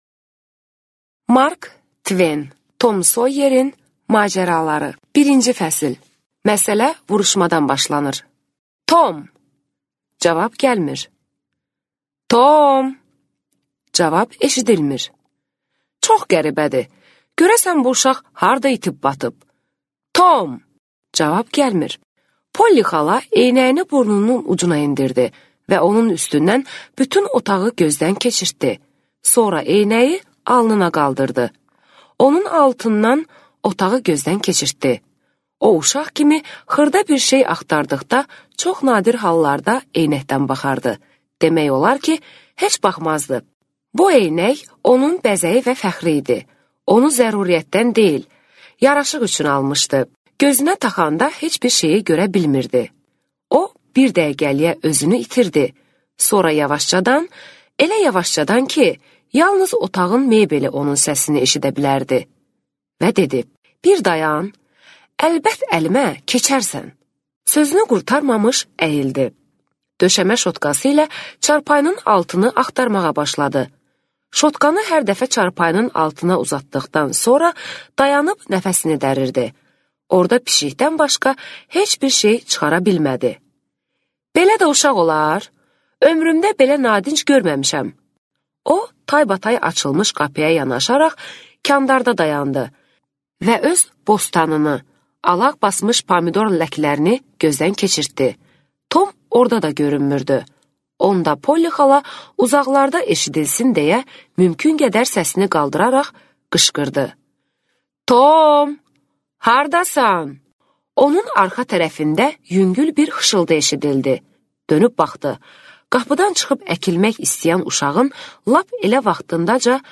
Аудиокнига Tom Soyerin macəraları | Библиотека аудиокниг